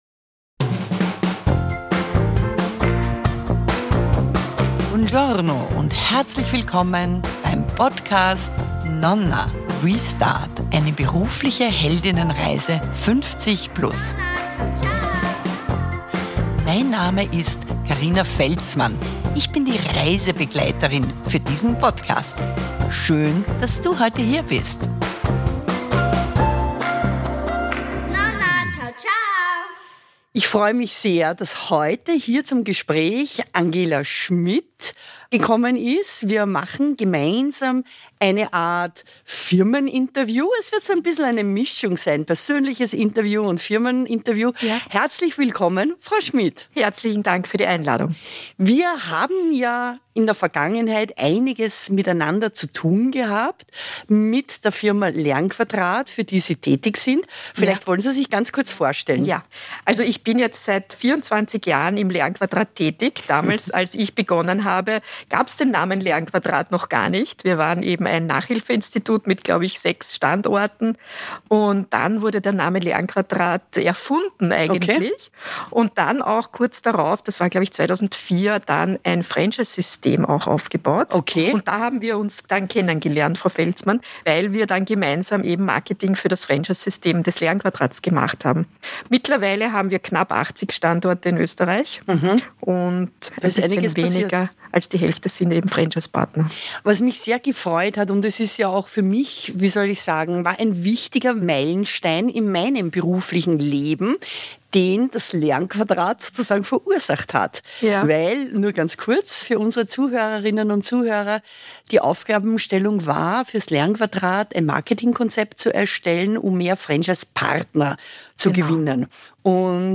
Firmengespräch
Sie lacht.